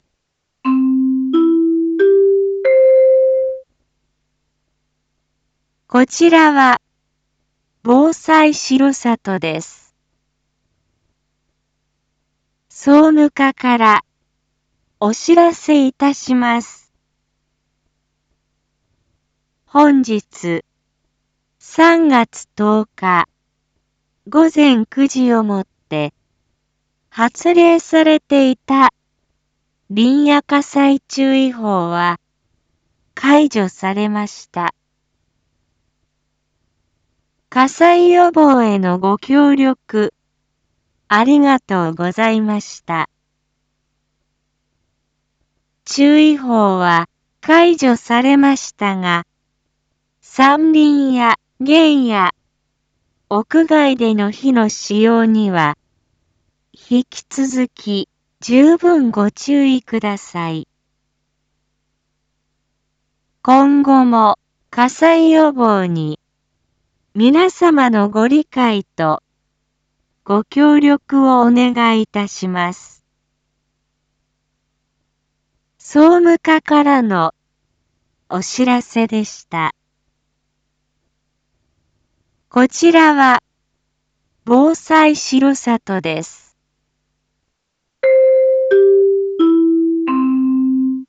Back Home 一般放送情報 音声放送 再生 一般放送情報 登録日時：2026-03-10 10:01:33 タイトル：林野火災注意報が解除されました インフォメーション：■ 解除日時 令和8年3月10日 午前9時00分 発令されていた「林野火災注意報」は解除されました。